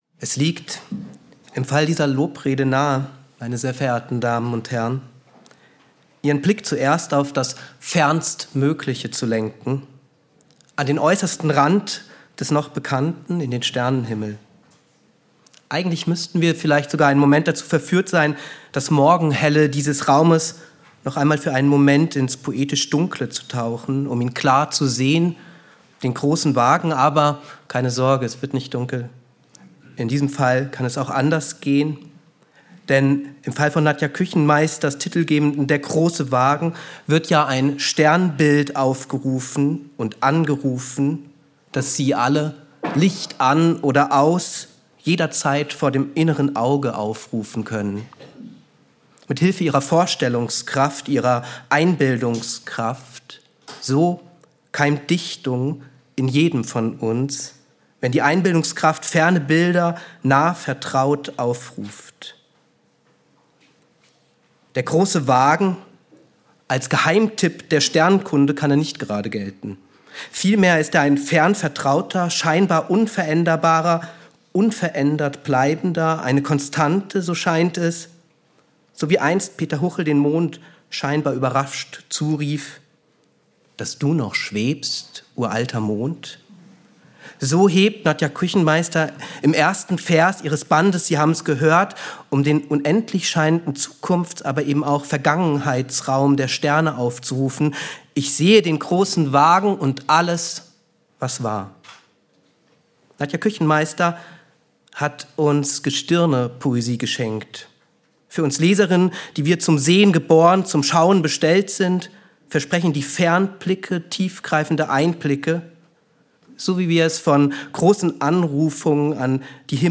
Laudatio